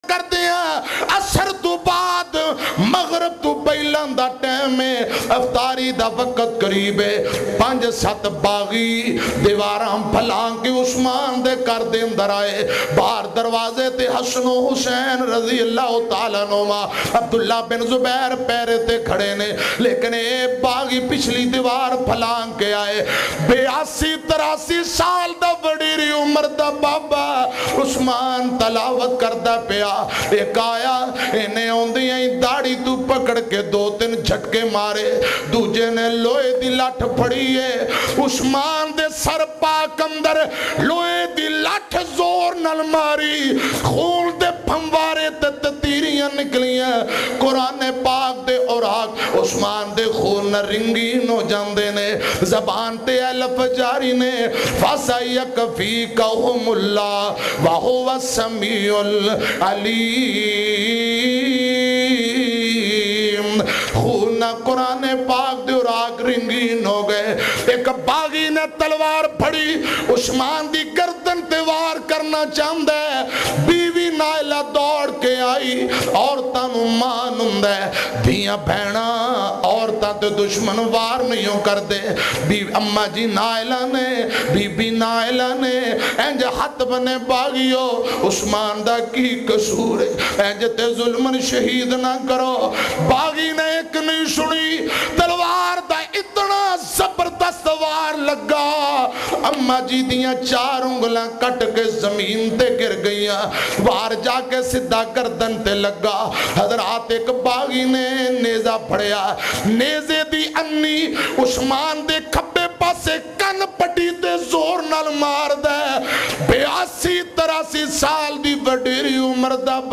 Hazrat Usman R.A Ki Shahadat Ka Waqia bayan mp3